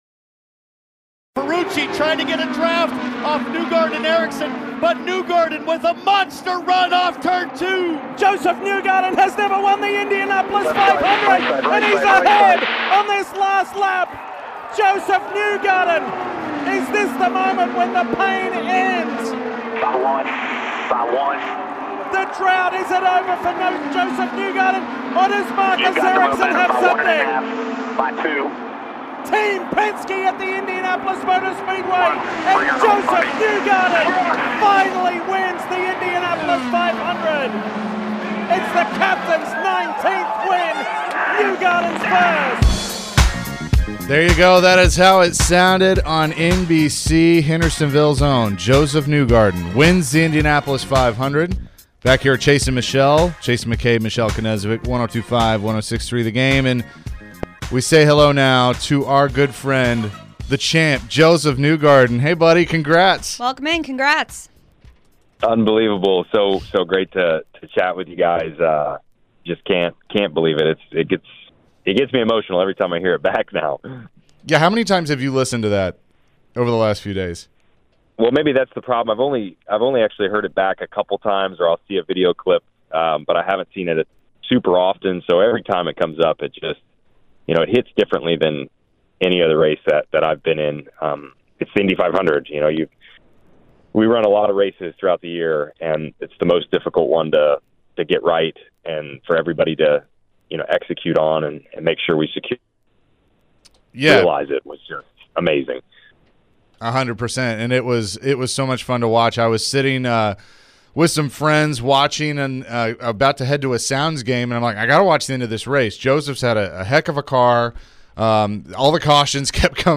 Josef Newgarden Interview 5-30-23